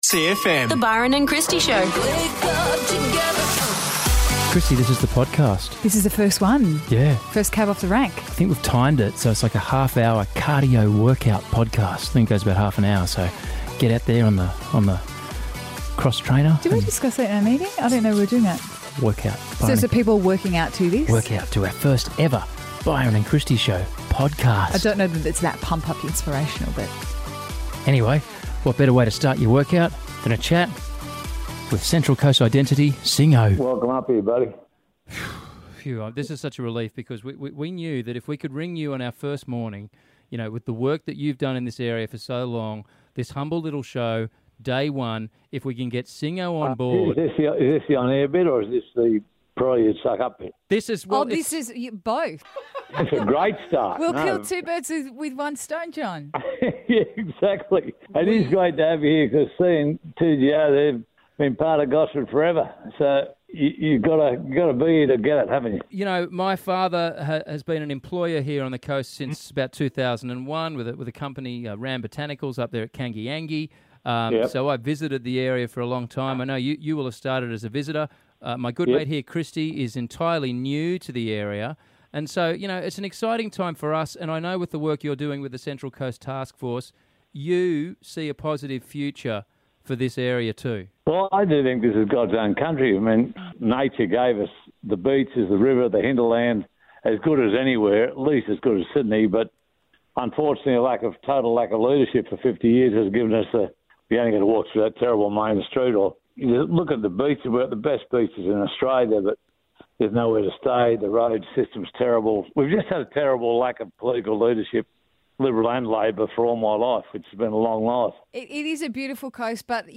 - The show's first tweets - Australian Reptile Park bring wombats and stuff in - An interview with One Direction - Nikki Webster guest co-hosts and we do Me Too with her - Do we combine with Newcastle and claim them as our own for the Asian Cup?